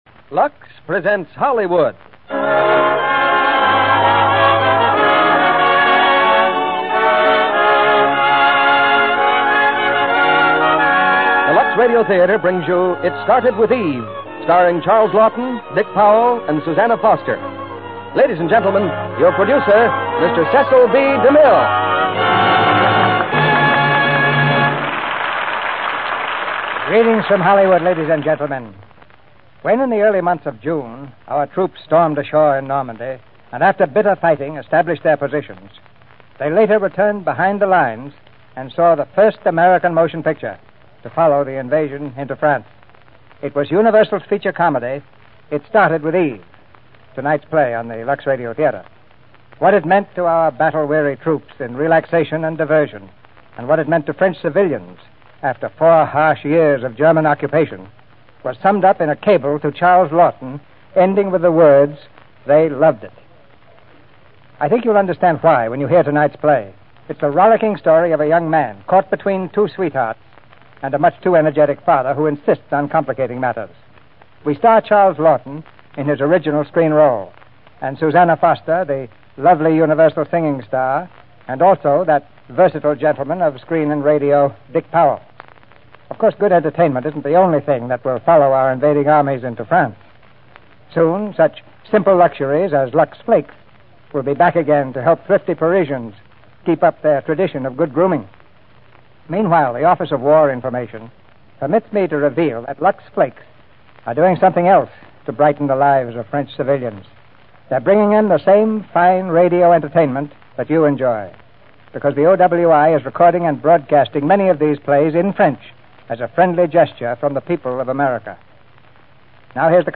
starring Charles Laughton, Susanna Foster, Dick Powell